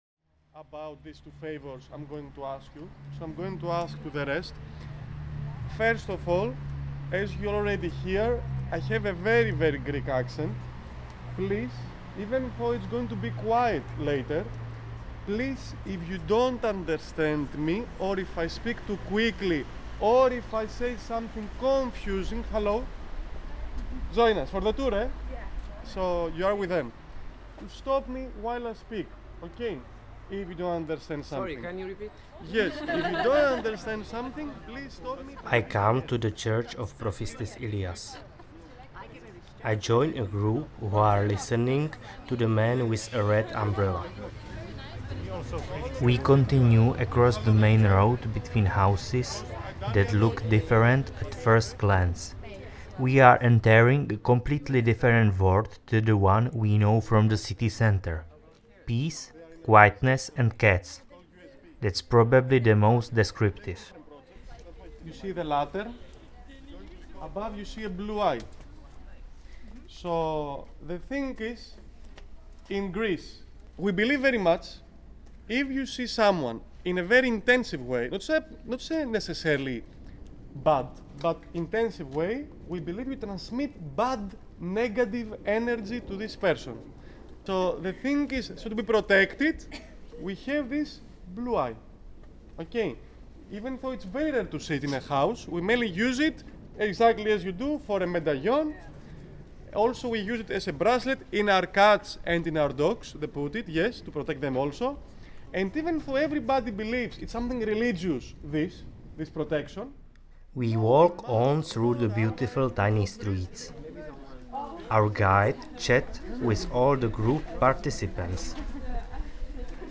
The part of a tour is also a small concert of Rebetiko, tradicional greek music.
thessaloniki-free-city-tour.mp3